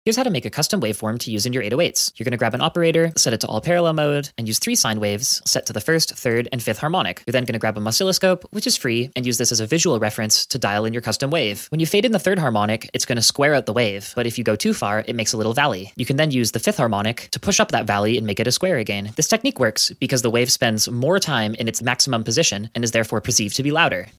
Custom 808 waveforms in seconds sound effects free download
Custom 808 waveforms in seconds with Ableton Operator! 🌀 Learn how to shape your bass like a pro.